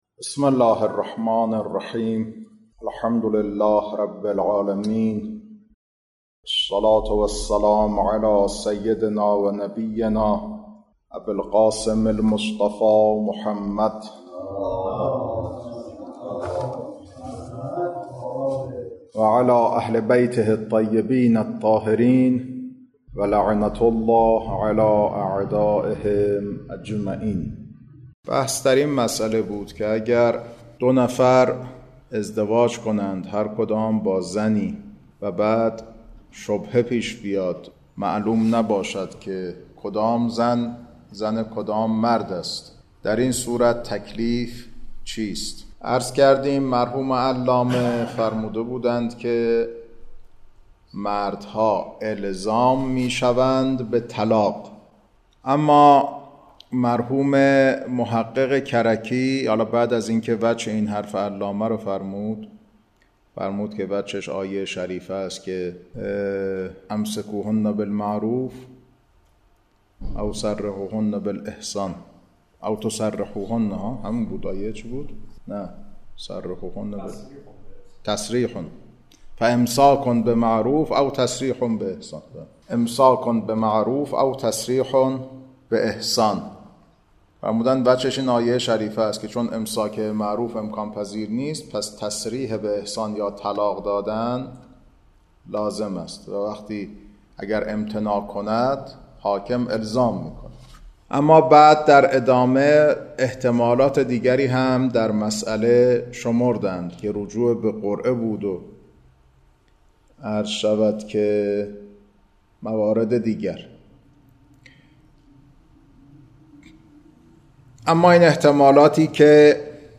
کلاس‌ها خارج فقه